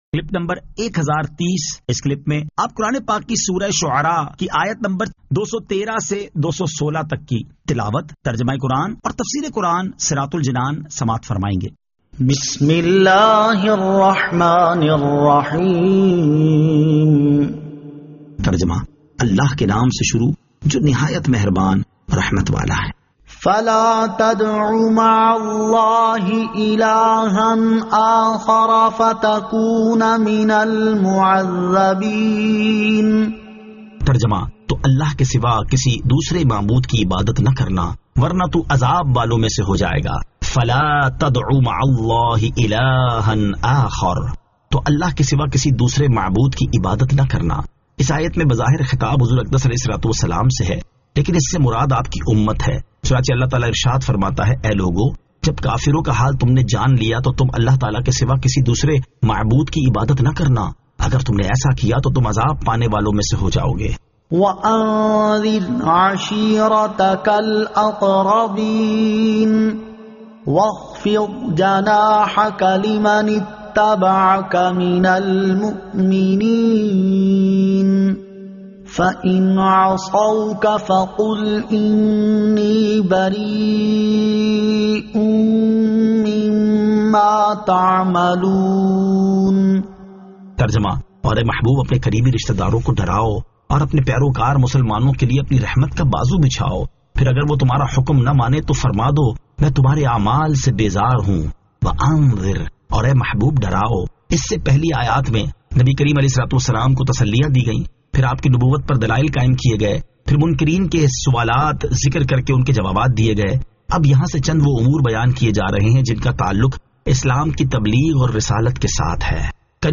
Surah Ash-Shu'ara 213 To 216 Tilawat , Tarjama , Tafseer